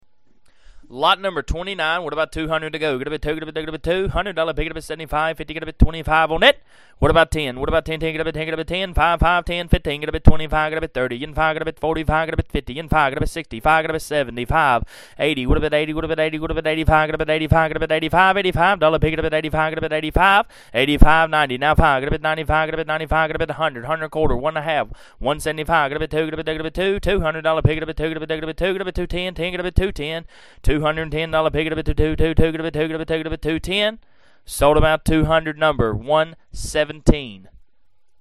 Hear the chant of a Champion!
chant1.mp3